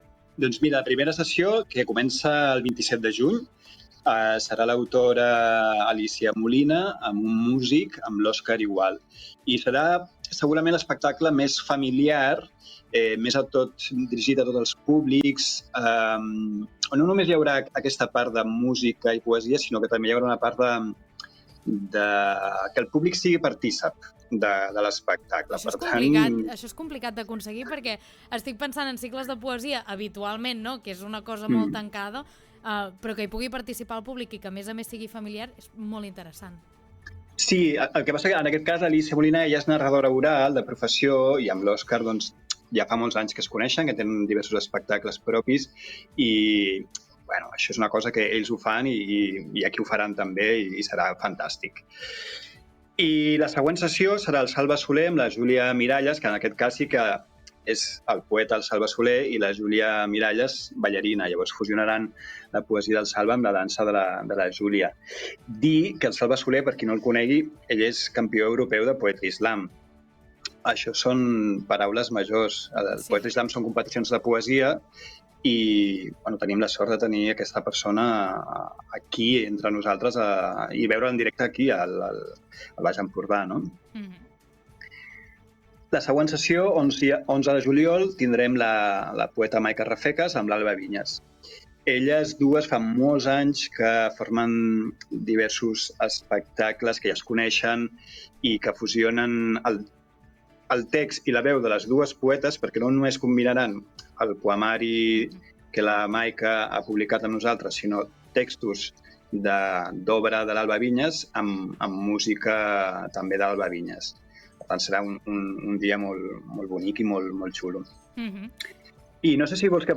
El cicle de poesia escènica “El Pati de Can Gil” torna aquest estiu amb quatre espectacles que es faran a Corçà i la Bisbal d’Empordà. En una entrevista al Supermatí